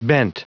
Prononciation du mot bent en anglais (fichier audio)
Prononciation du mot : bent